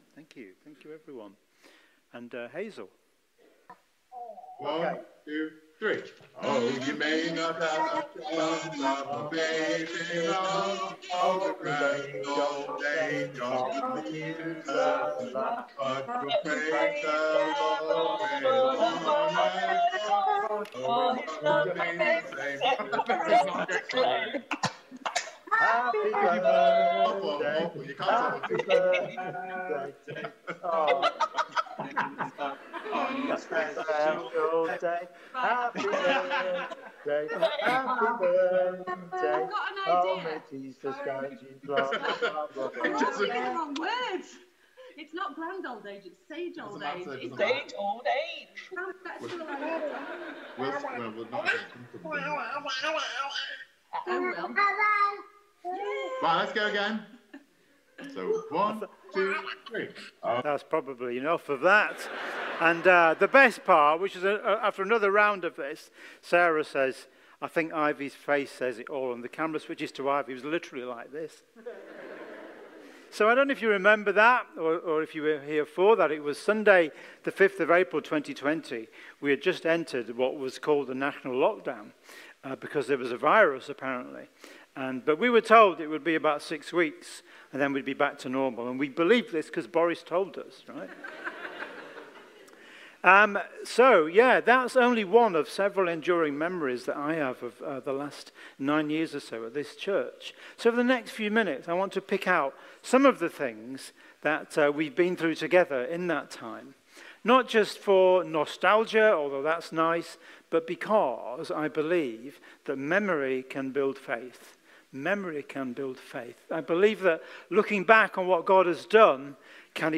Series: Live stream Passage: Colossians 3:12-17 Service Type: Sunday Morning